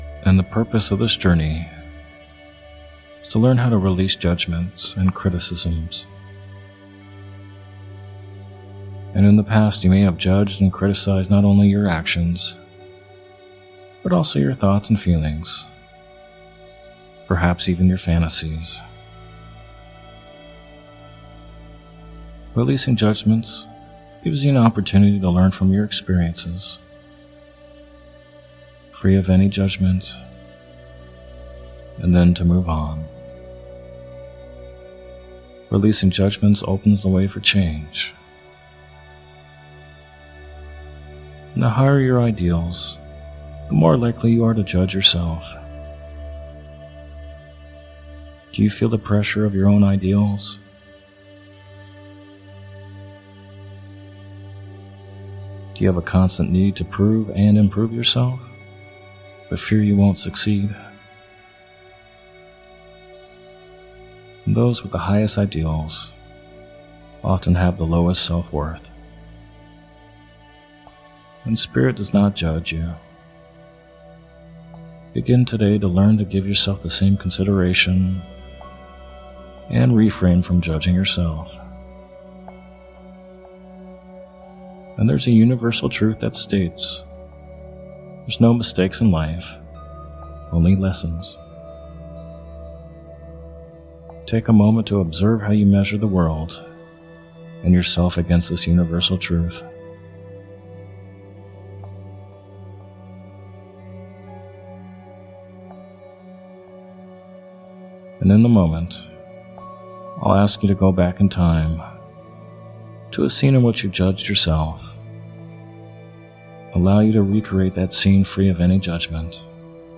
In this hypnosis audio, you’ll be guided to release any judgments you have towards yourself and other people.